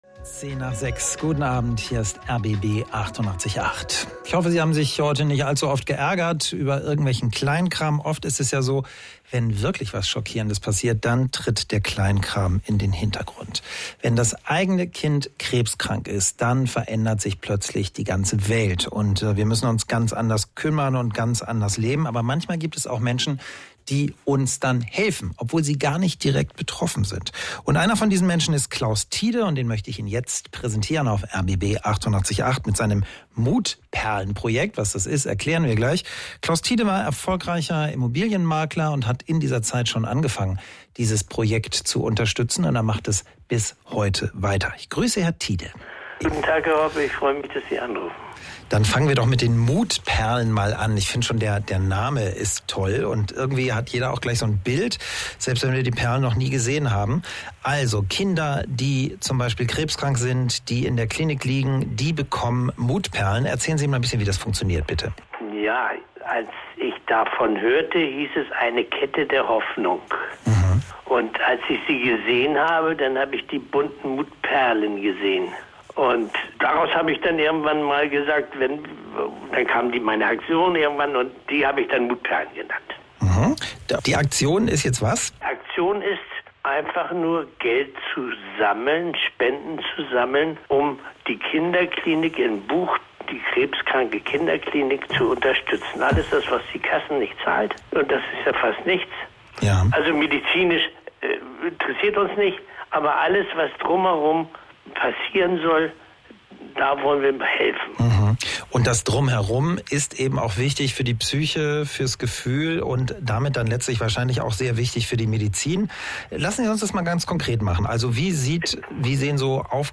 RBB Interview
Hier finden Sie einen aktuellen Beitrag der als Interview bei RBB am 08. Oktober gelaufen ist.